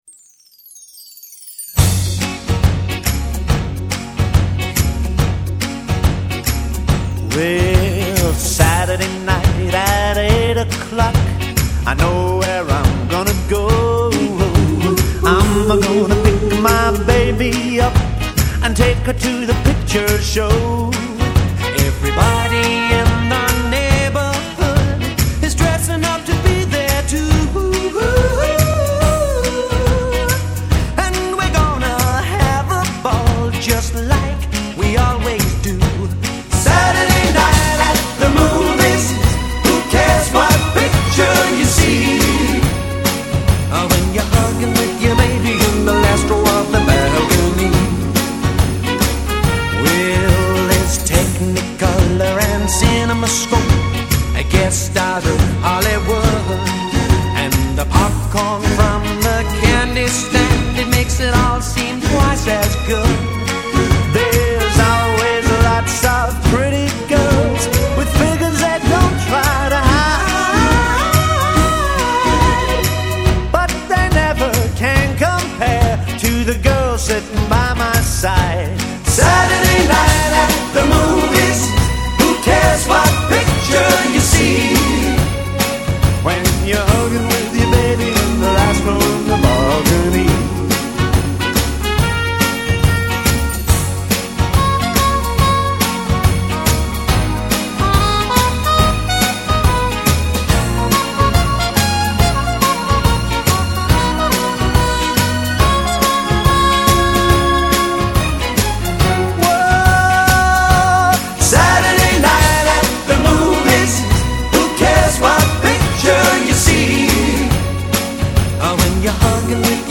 британского дуэта